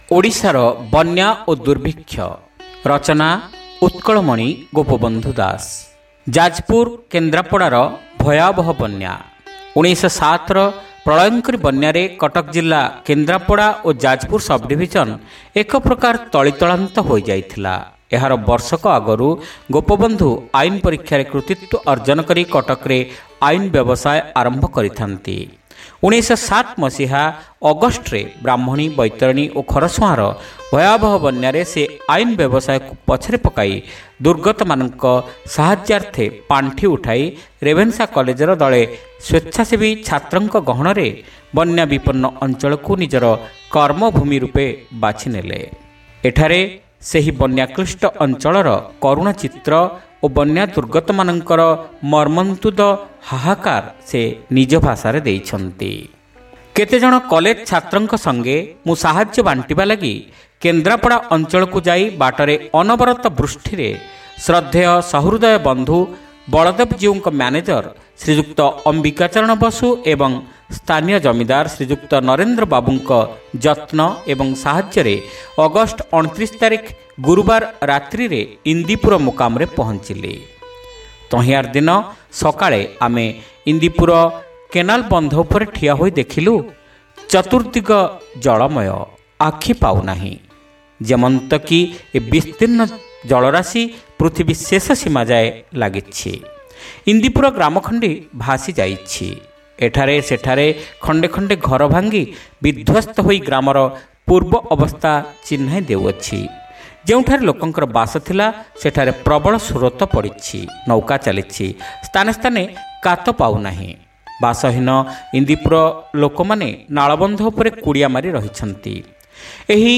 Audio Story : Odisha ra Banya o Durbikhya